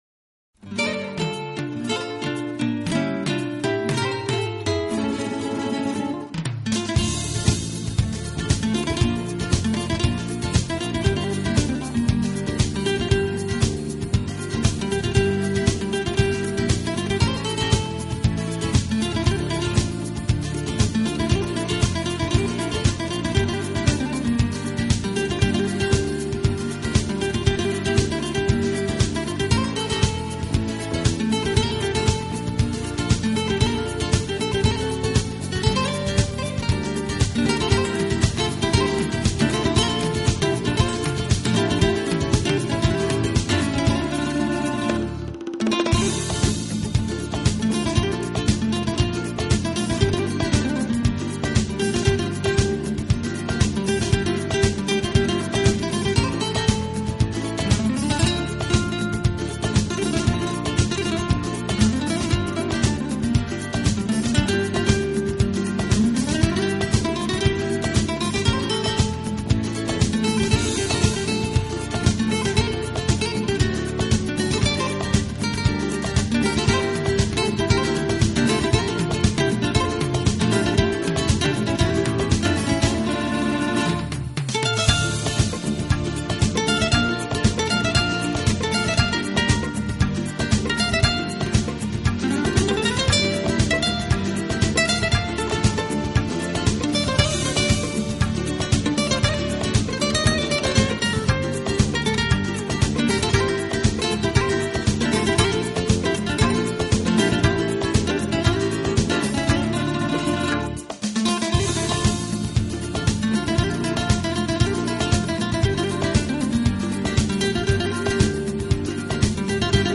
专辑音色清脆动人且温馨旖丽，不禁展示了精彩绝伦的空间感，而且带出吉他音箱共鸣
浪漫吉他曲，经典西洋乐，音符似跳动的精灵，释放沉睡已久的浪漫情怀，用吉他的清